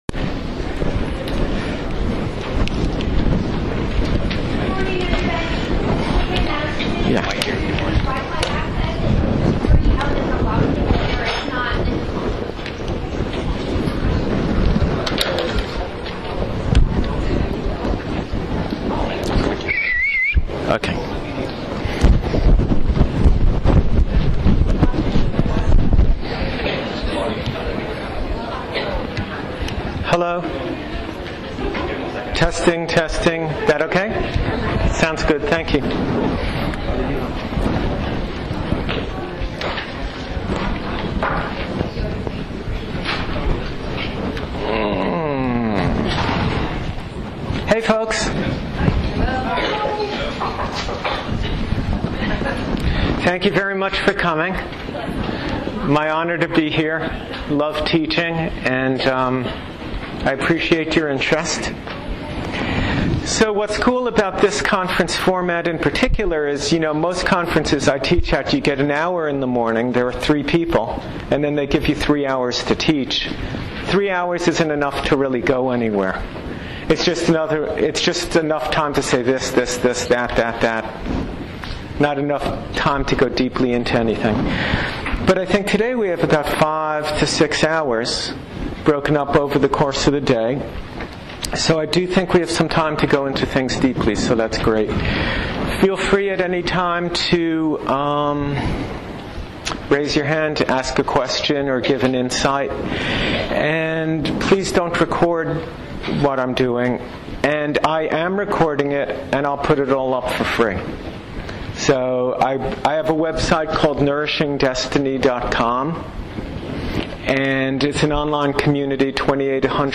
Please enjoy the first two audios from my talk in Austin, TX. Included here are my ten minute introduction to the general assembly as well as the first session(1:47).